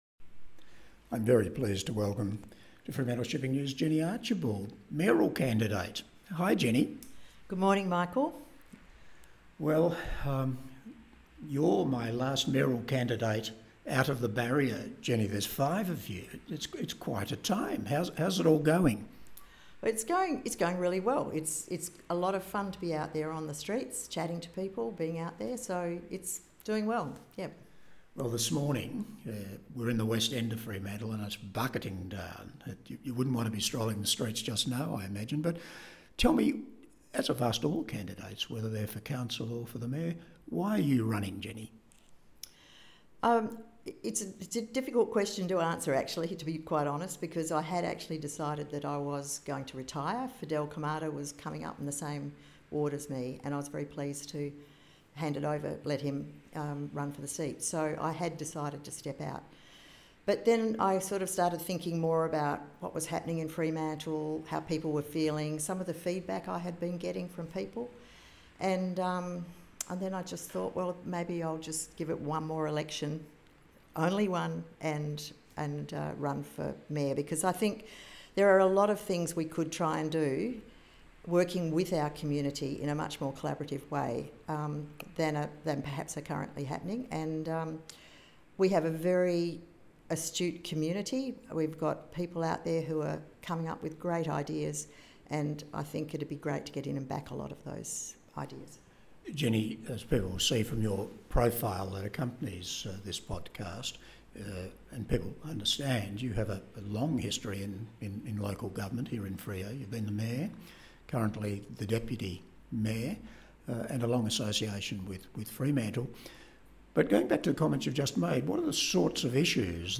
We have invited all candidates to be interviewed by our Editor in a getting-to-know-you podcast format explaining why they are running.